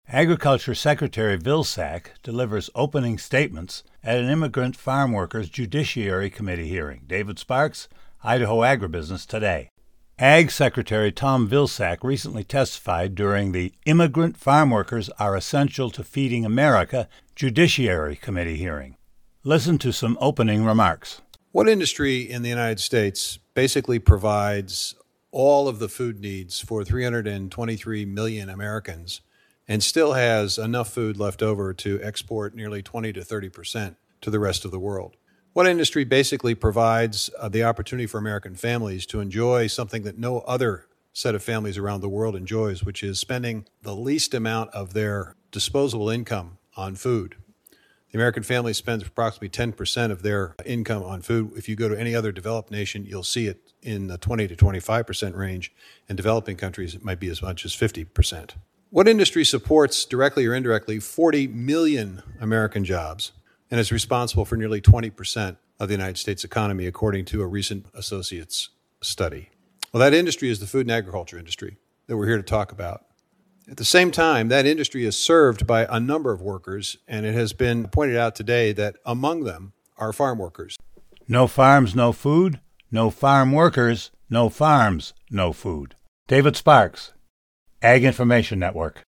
Vilsack Speech
Secretary of Agriculture Tom Vilsack recently testified during the “Immigrant Farmworkers are Essential to Feeding America” Judiciary Committee hearing, the first-ever appearance by an Agriculture Secretary before the full Senate Judiciary Committee. During his opening statement, Vilsack discussed the important role immigrant farmworkers have in the food and agriculture industry and the overall economy.